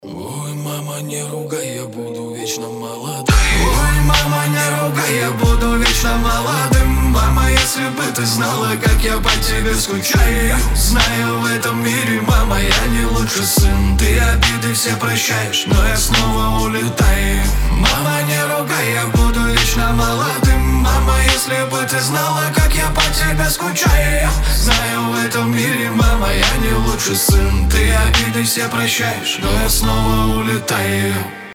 мужской голос
русский рэп
пацанские